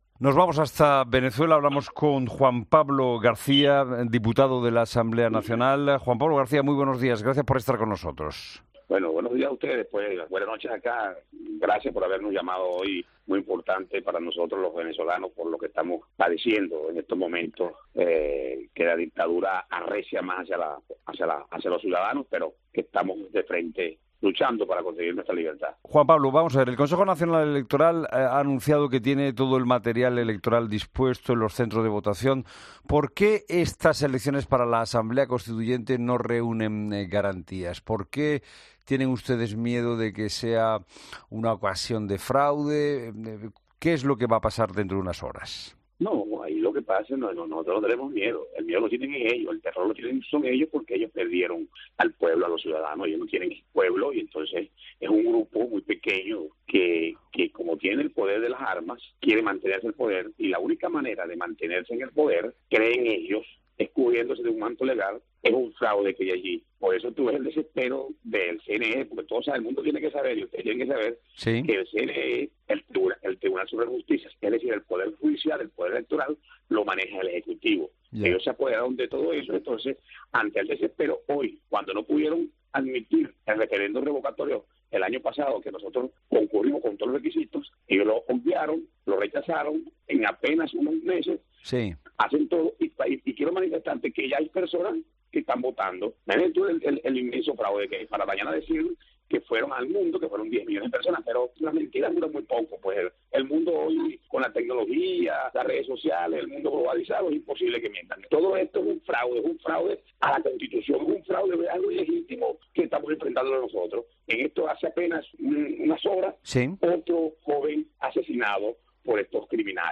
“El miedo lo tiene Maduro, el ya perdió al pueblo”, Juan Pablo García, diputado de la Asamblea Nacional